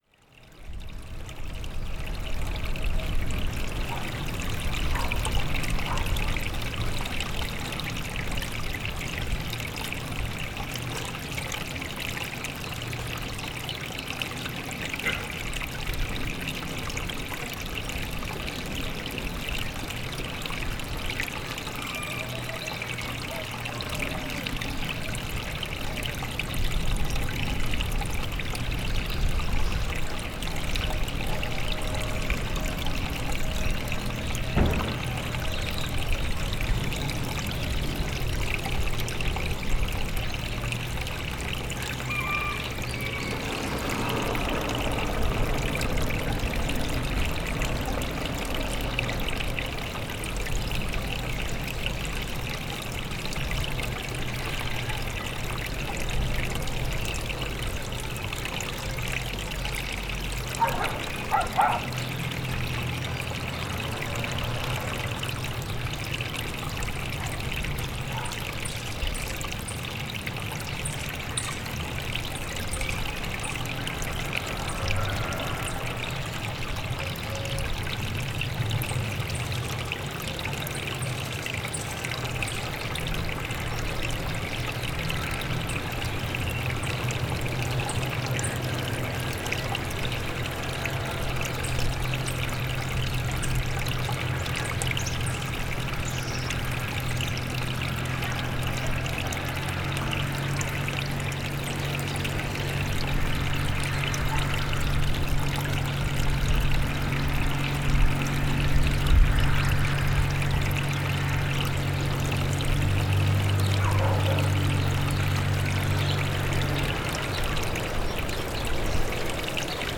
NODAR.00533 – Boa Aldeia, Farminhão e Torredeita: Escoamento de águas pluviais junto à Igreja de Boa Aldeia + Trator